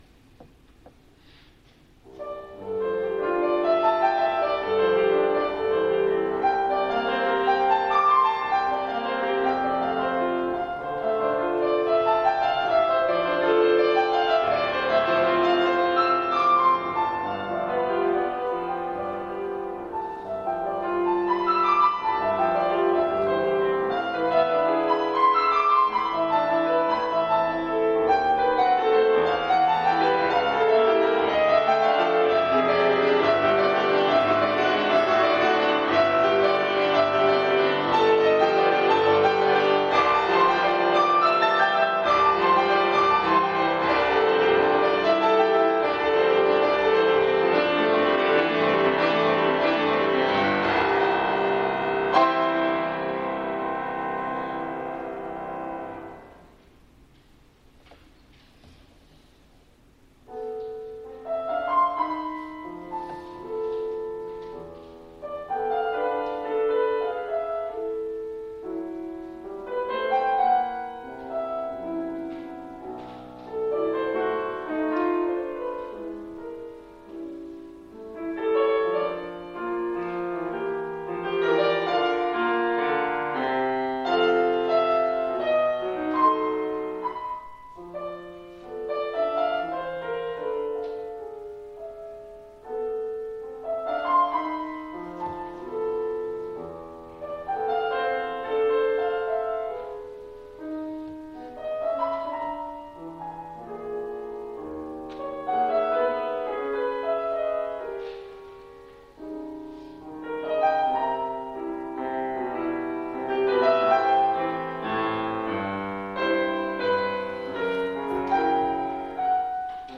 スクリャービン:24のプレリュード Op.11 Salle Cortot （Piano-Steinway